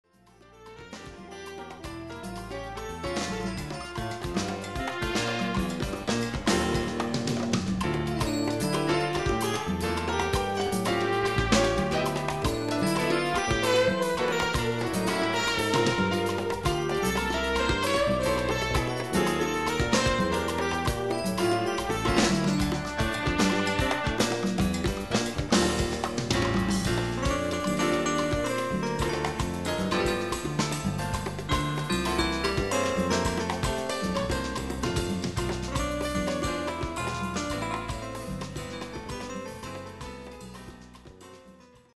Latin jazz
Category: little big band
Style: mambo
Solos: tenor sax, trumpet 1, vibes, piano